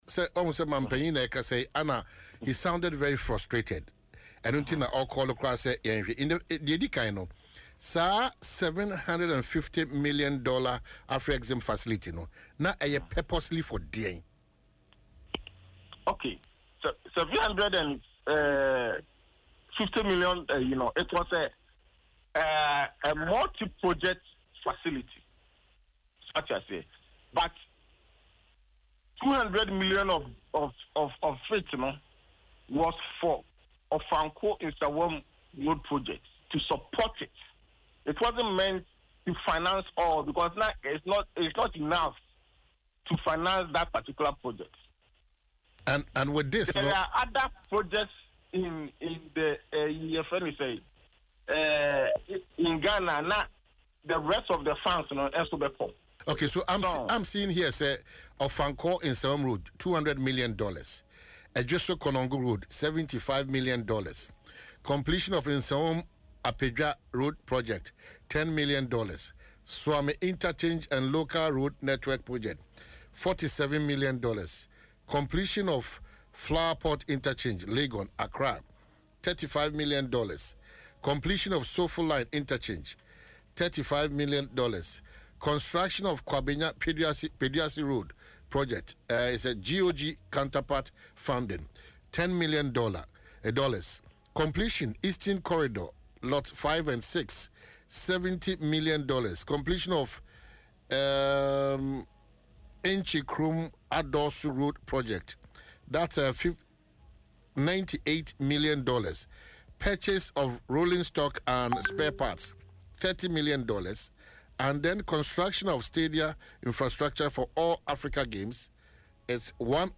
Speaking on Adom FM’s Dwaso Nsem, the Bantama MP explained that the $750 million Afreximbank loan obtained by the previous government was a multi-project facility, with $200 million specifically earmarked for the Ofankor–Nsawam road.